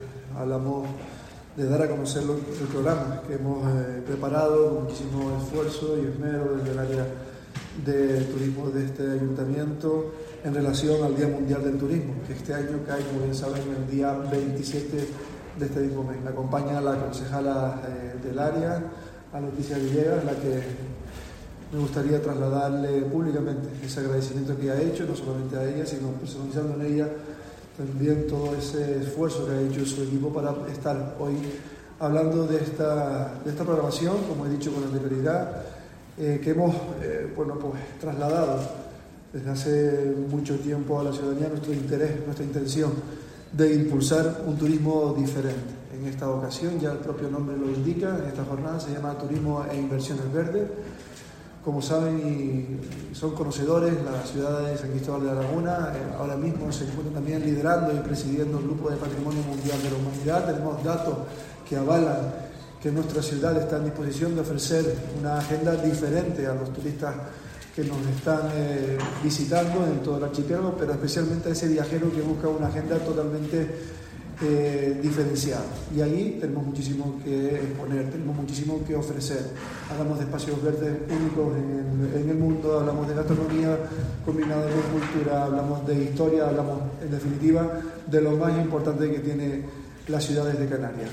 El acto de presentación de esta agenda cultural y de ocio tuvo lugar este lunes, 25 de septiembre, en la Casa de los Capitanes y contó con la participación del alcalde lagunero, Luis Yeray Gutiérrez, y la concejala responsable del Área, Leticia Villegas.
Presentación Programa Día Mundial del Turismo por el Alcalde Luis Yeray Gutiérrez